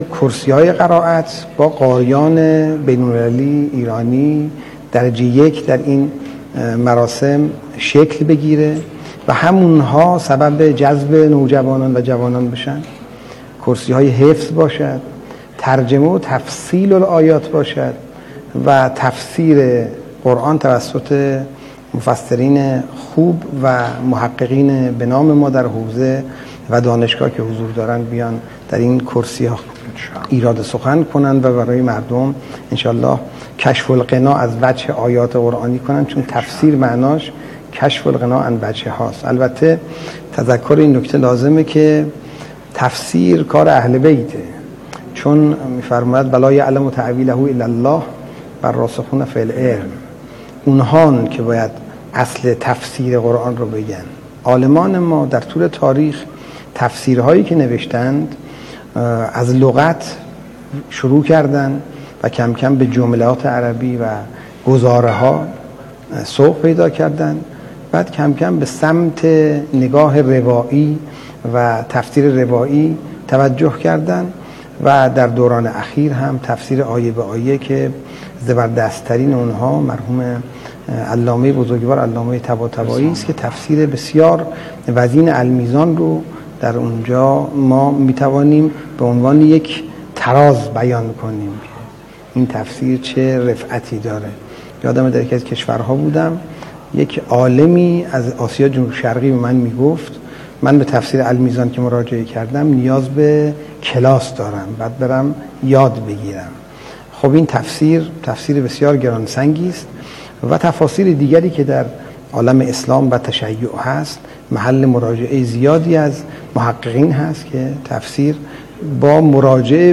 رئیس سازمان اوقاف و امور خیریه در مراسم افتتاحیه کرسی‌های تلاوت و تفسیر قرآن کریم با اشاره به اینکه دو هزار مرکز از بقاع متبرکه قابلیت دارد که برای اجرای فعالیت‌های قرآنی در اختیار فعالان قرآنی قرار بگیرد، گفت: بقاع متبرکه یکی از بهترین اماکنی است که می‌تواند از قاریان، مدرسان قرآن و کسانی که می‌خواهند نهضت قرآن‌آموزی را در کشور توسعه دهند، میزبانی کند.